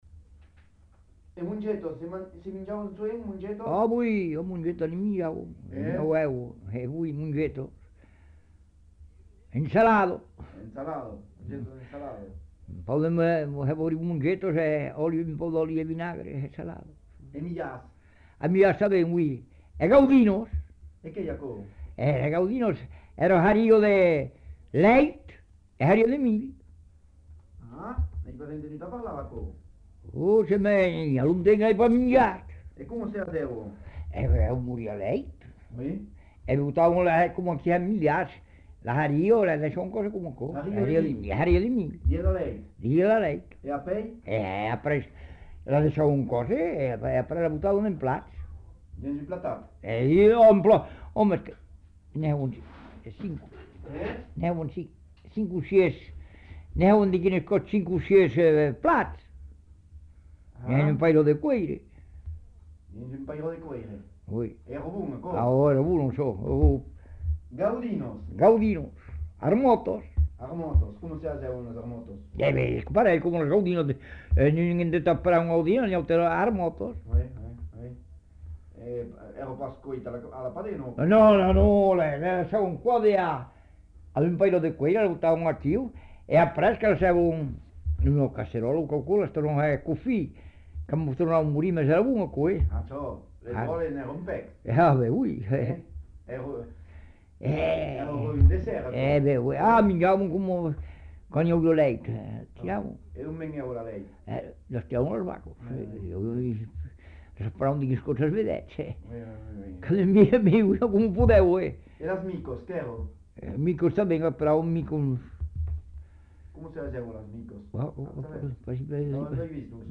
Aire culturelle : Savès
Genre : témoignage thématique